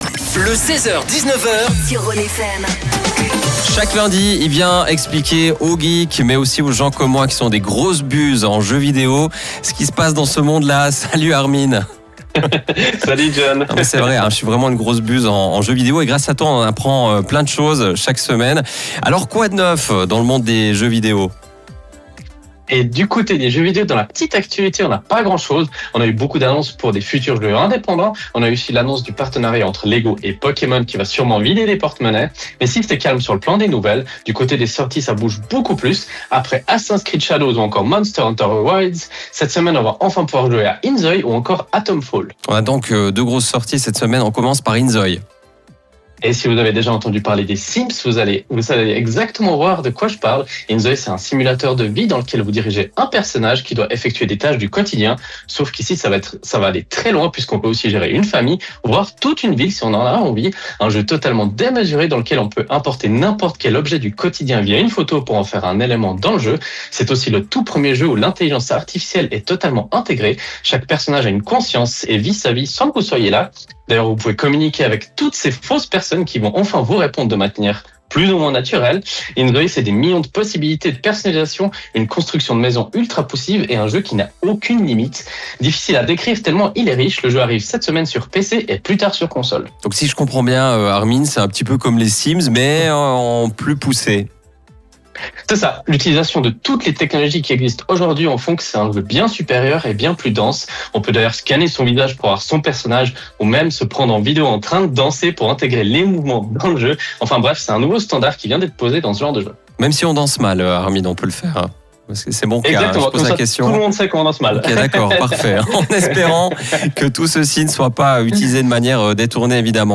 Comme tous les lundis, nous avons la chance de présenter notre chronique gaming sur la radio Rhône FM. Une capsule gaming qui retrace l’actualité du moment.
Vous pouvez réécouter le direct Rhône FM via le flux qui se trouve juste en haut de l’article.